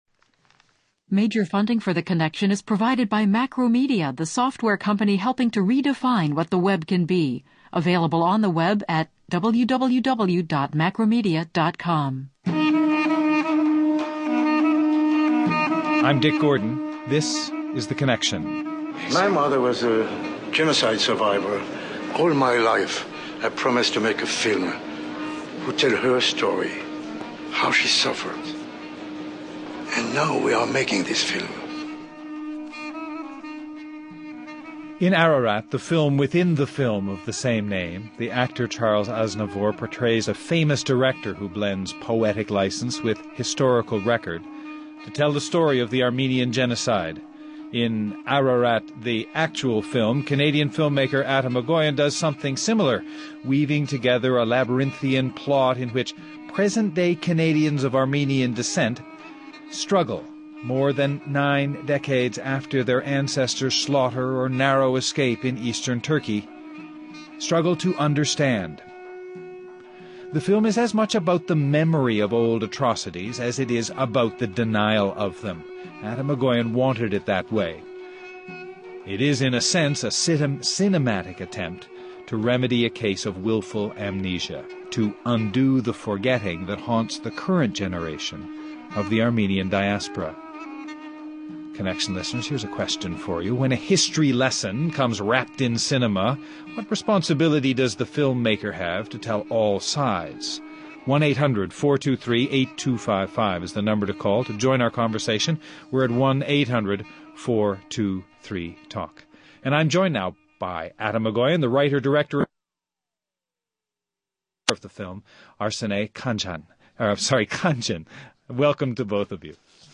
Rather, Ararat is about the denial of it, and the way that willful forgetting haunts the Armenian Diaspora still. Atom Egoyan and his wife and star of the film, Arsinee Khanjian join us.